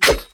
sword_hit.ogg